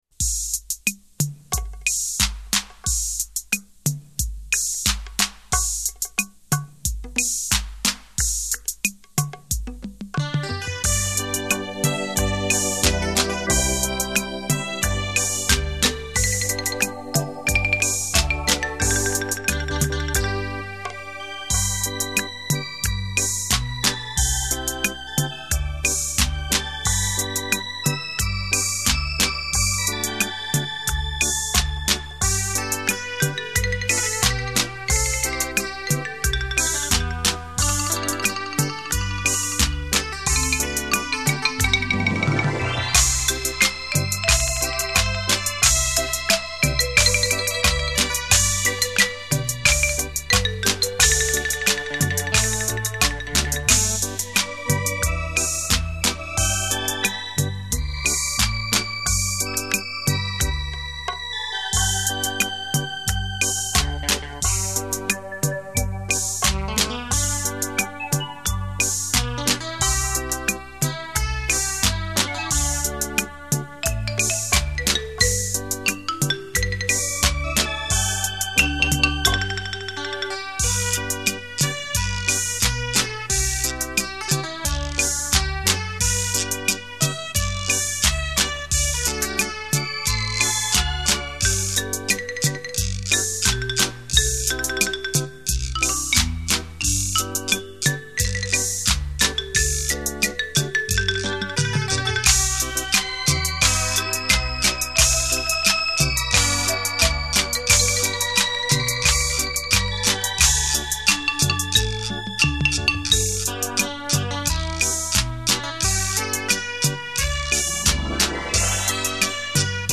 繞場立體音效 發燒音樂重炫 有音樂的地方就有
節奏強勁，旋律優美，曲曲動聽電聲